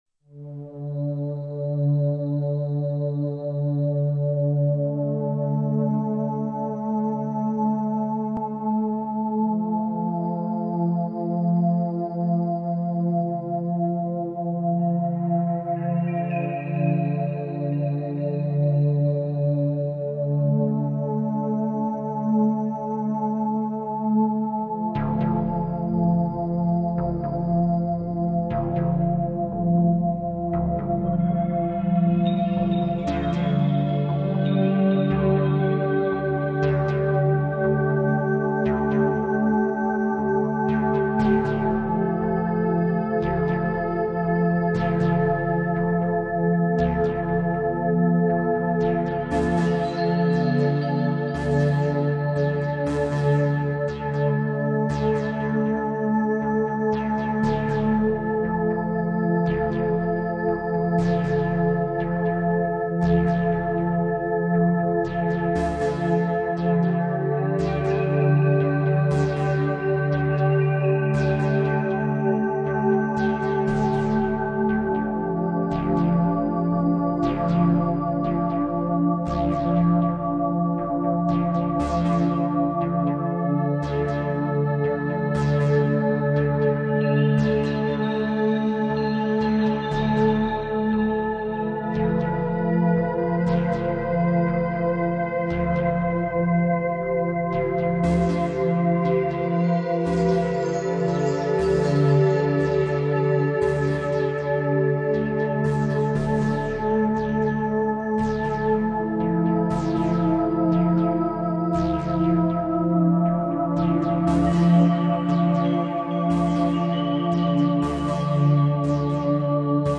ambient project
Thus it is often soothing, but never too much so...